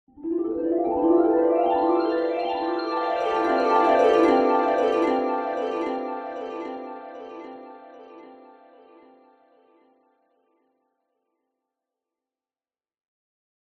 Магический звон